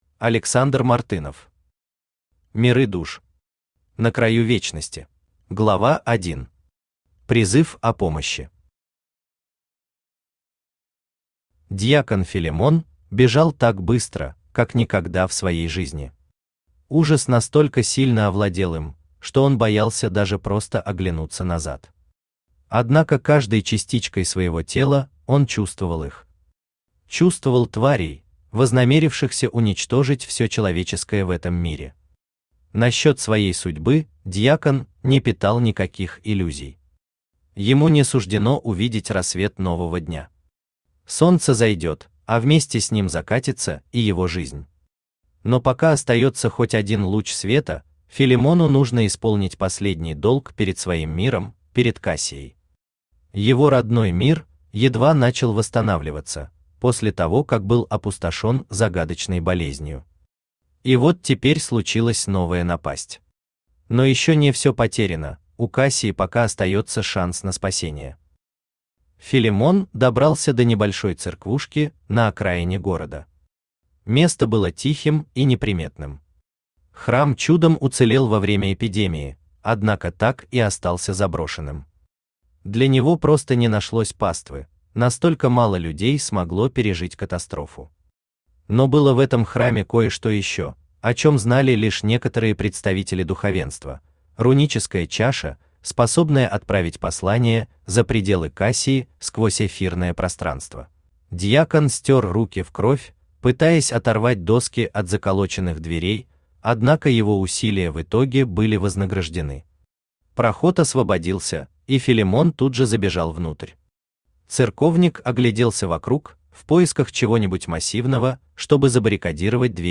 На краю вечности Автор Александр Мартынов Читает аудиокнигу Авточтец ЛитРес.